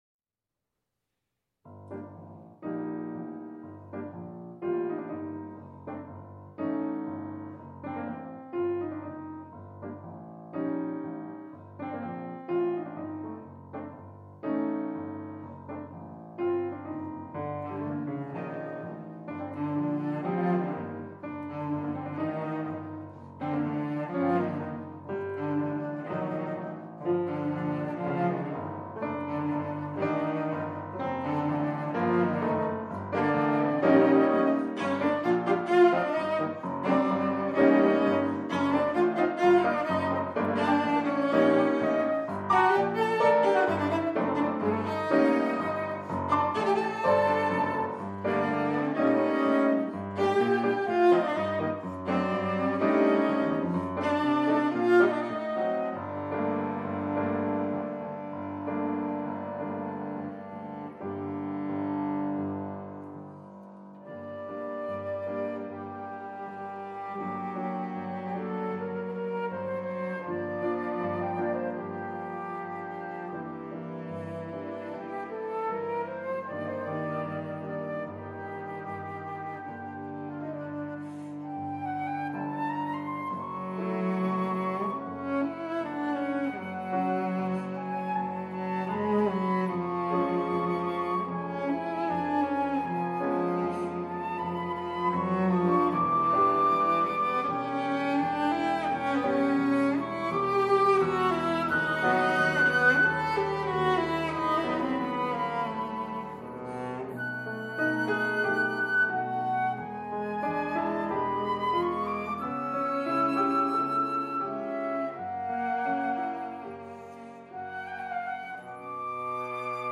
Tango (Arr. José Bragato)
Founded in the spring of 2022 in the Ottawa-Gatineau region, Ensemble Passamano emerged from the shared desire of its members to explore and discover the repertoire written for flute, cello, and piano.